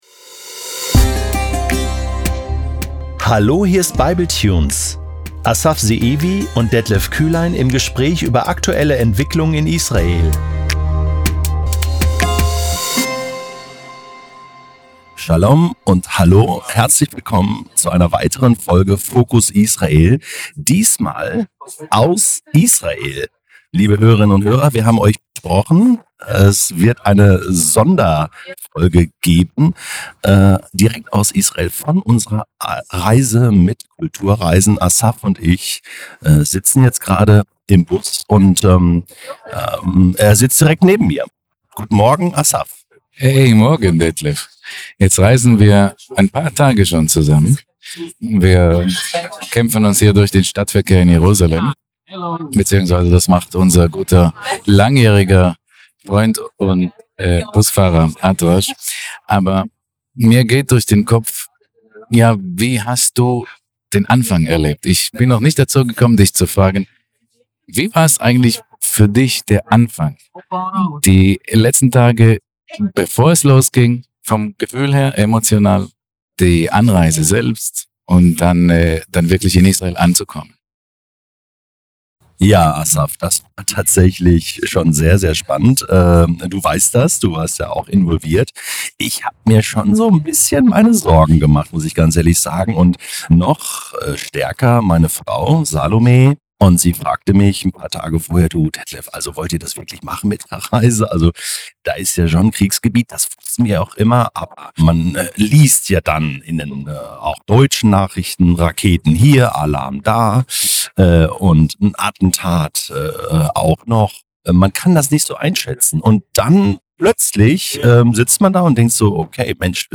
Alle Episoden anschauen: Fokus: Israel 01 Fokus: Israel – Reisetagebuch (Live aus Israel!) 23:42 Episode teilen: Facebook Twitter E-Mail Text: Hinweis: Die Soundqualität dieser Aufnahme ist leider nicht optimal und teilweise leicht übersteuert. Dies liegt daran, dass die Aufnahmen während der Busfahrt auf der Reise entstanden sind.